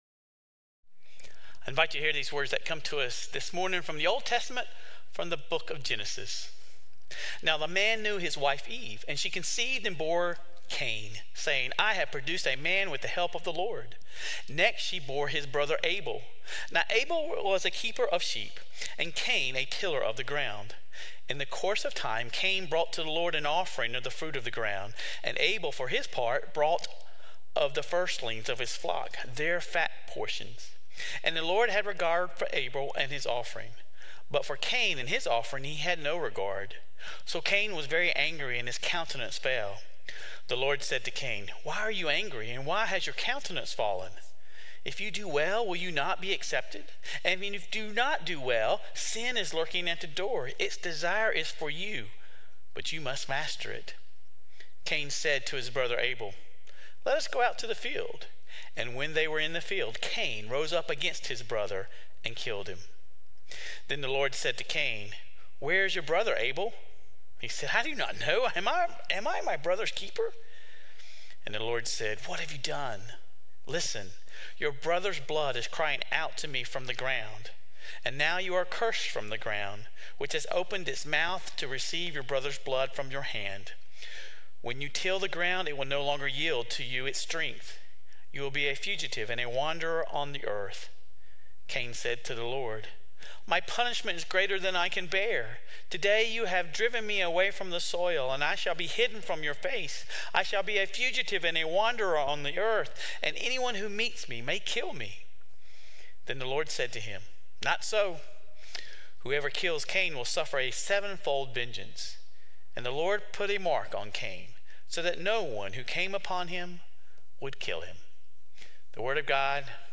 This week, we take a look at Jacob and Joseph. Sermon Reflections: How have you experienced favoritism or jealousy within your own family or relationships?